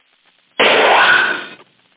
fire_wve.mp3